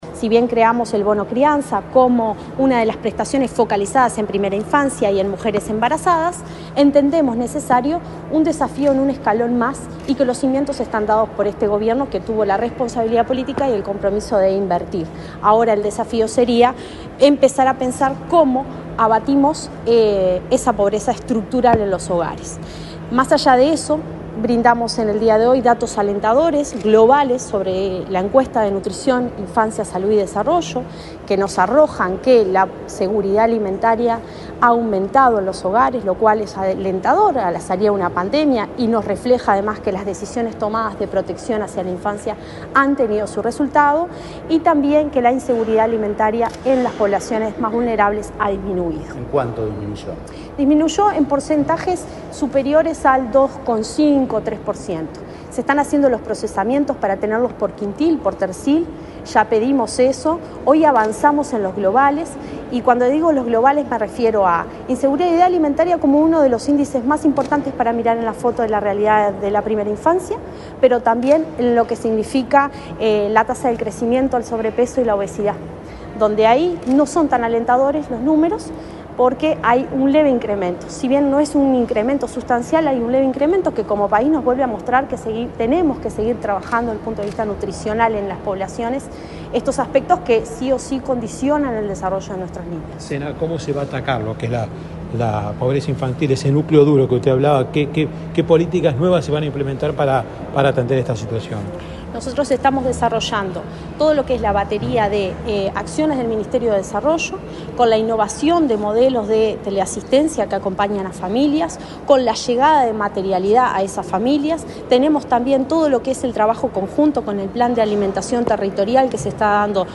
La directora nacional de Desarrollo Social del Mides, Cecilia Sena, dialogó con la prensa en Torre Ejecutiva, luego de participar del lanzamiento del